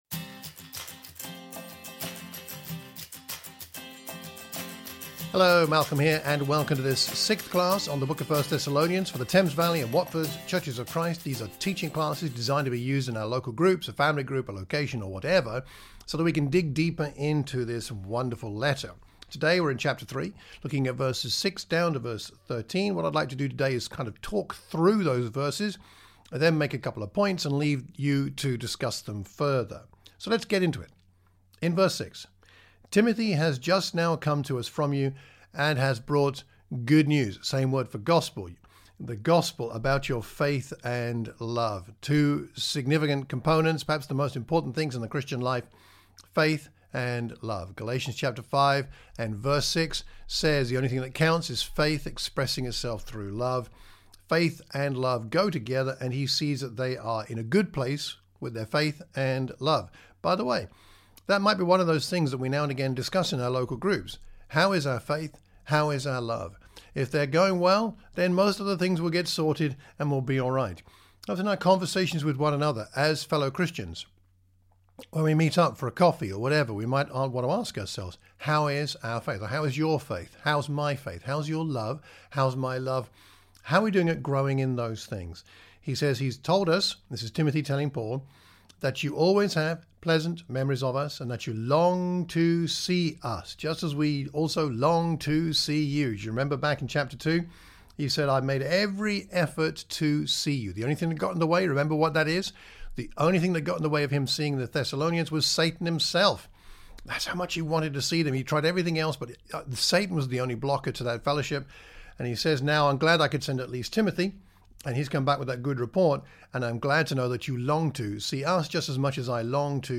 A teaching class for the Watford and Thames Valley churches of Christ.